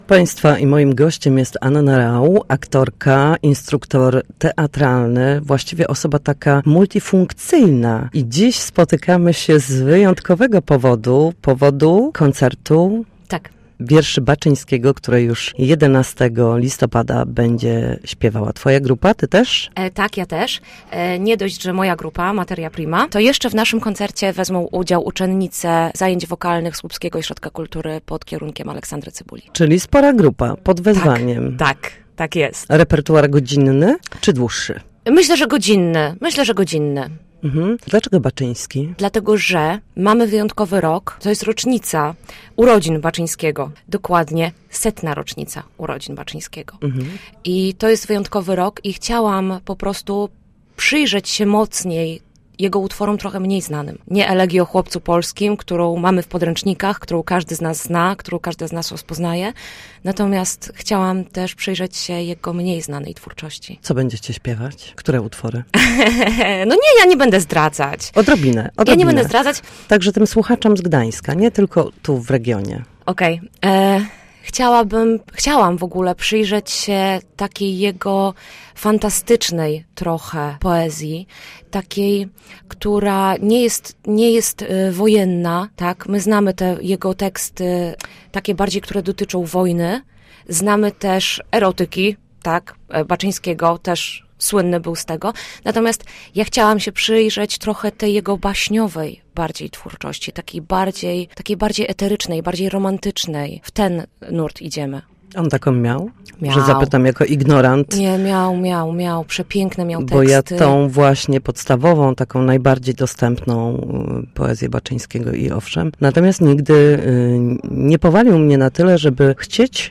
Gość Studia Słupsk Radia Gdańsk